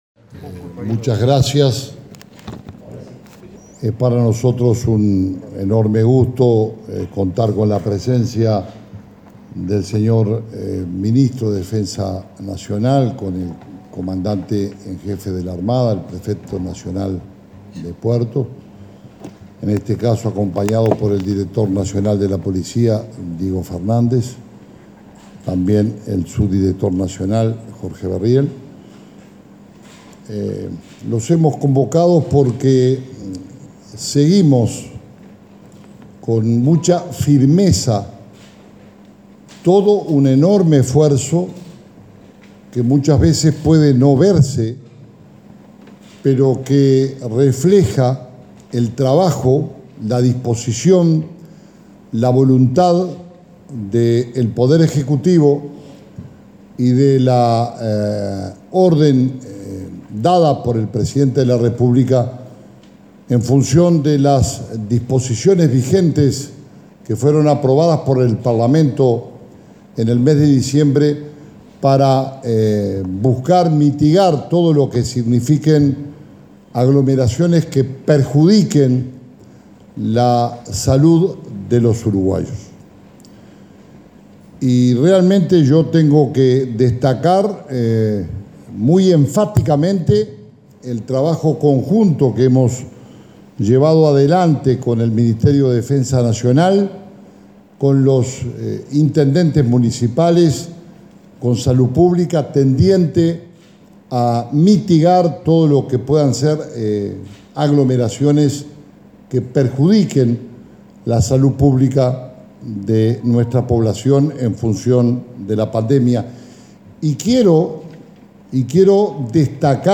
Conferencia de prensa de Interior y de Defensa sobre operativo para Carnaval
Los ministros de ambas carteras, Jorge Larrañaga y Javier García, informaron, este jueves 11 de febrero, acerca del operativo que se llevará a cabo